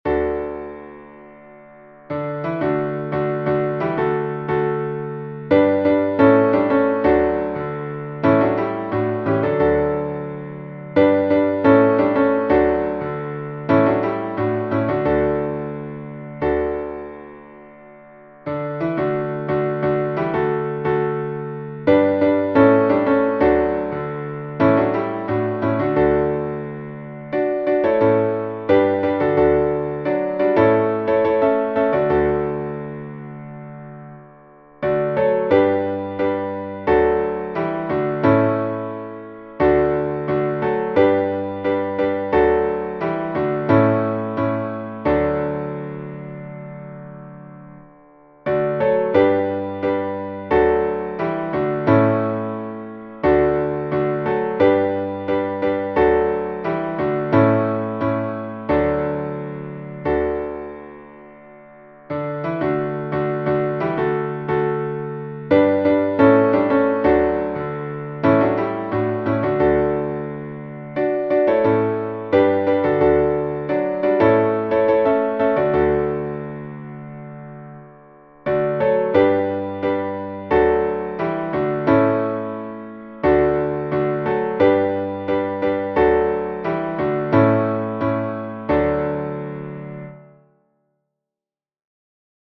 Chants de Noël Téléchargé par